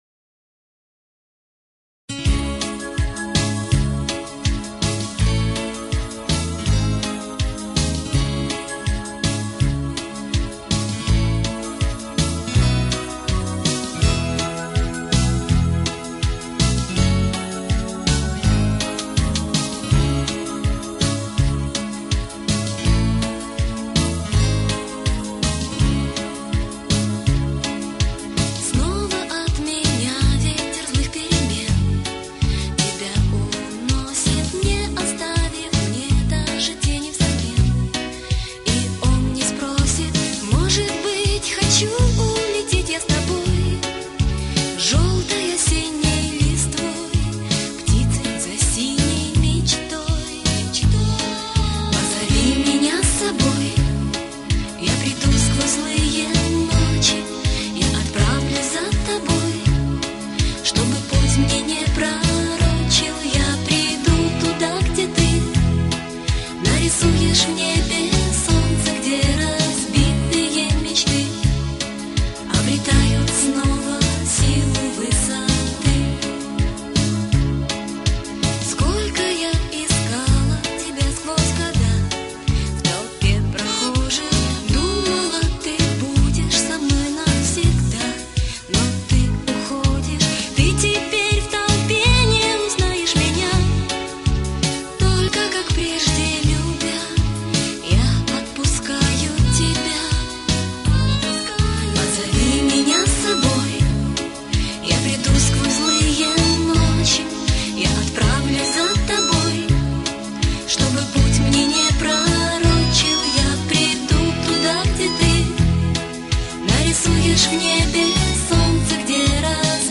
Авт. исп.